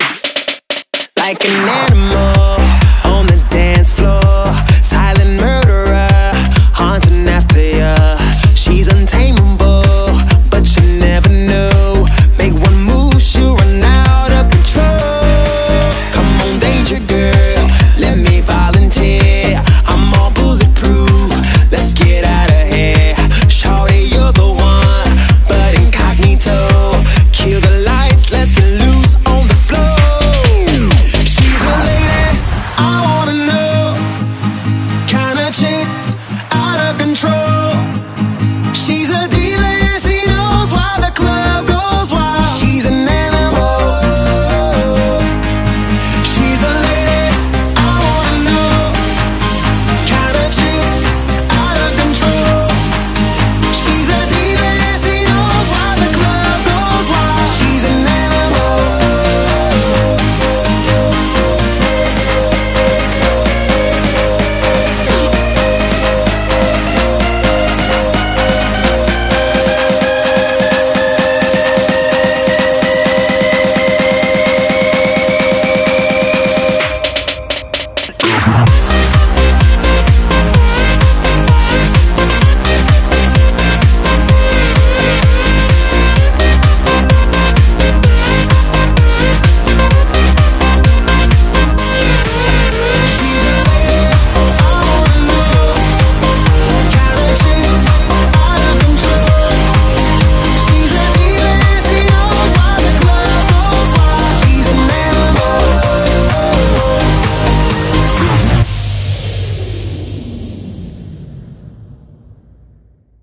BPM128
Audio QualityPerfect (High Quality)
Comments[ELECTRO HOUSE]